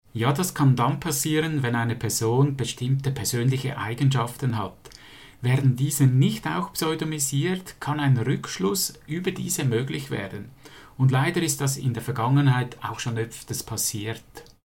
Dieses Interview gibt es auch auf Schwitzerdütsch!